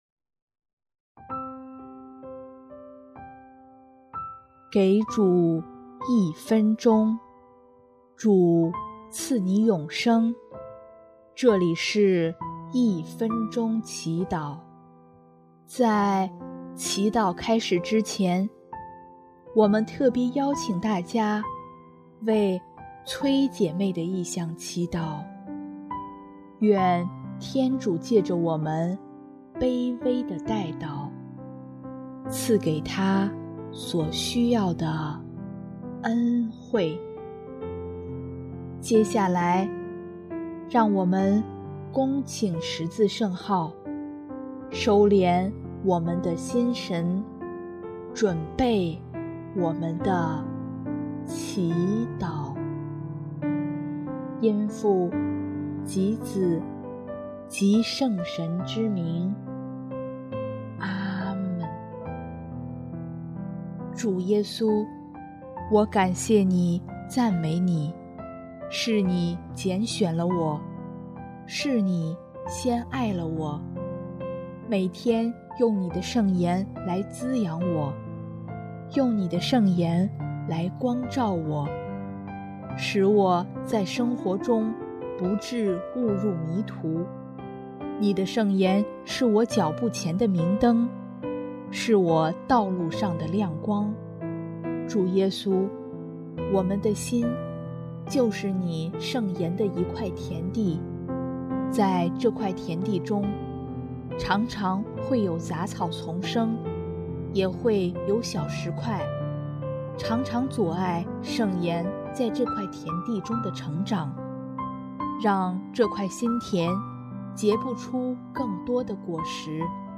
【一分钟祈祷】|7月24日 结出百倍果实好能光荣主